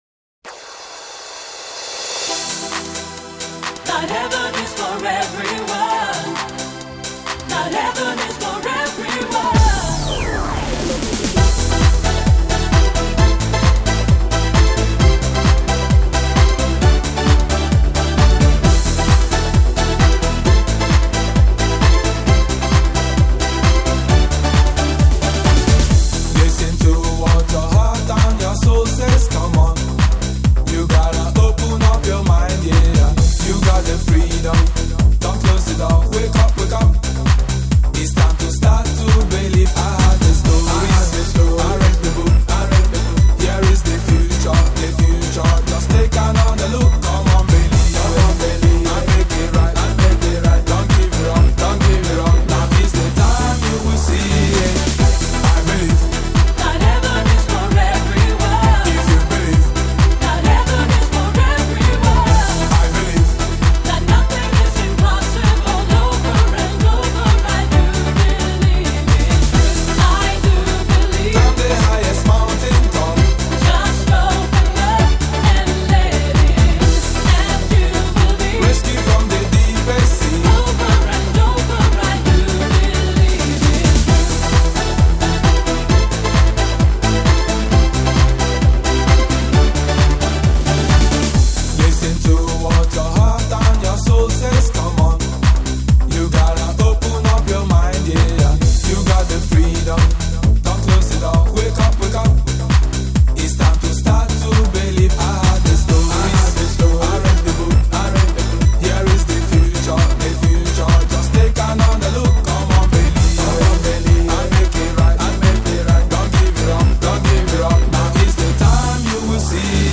Жанр: Eurodance